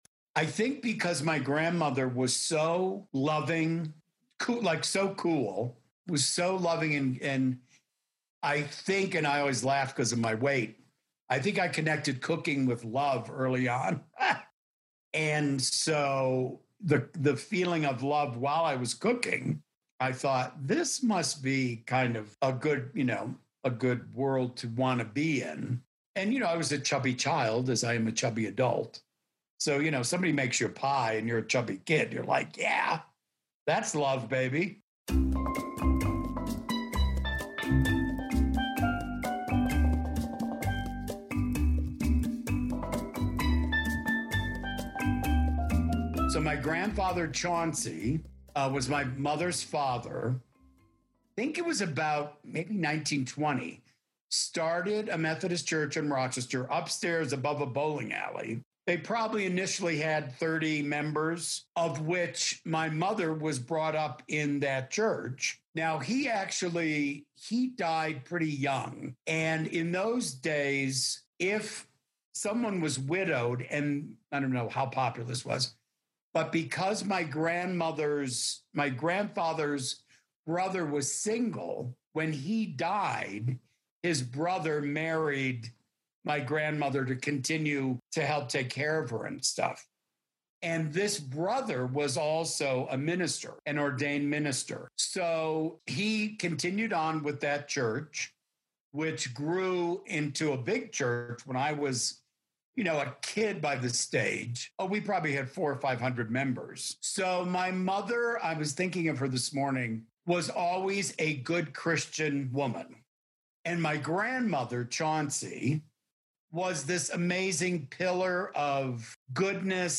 Episode Eleven: The Interview-Chef.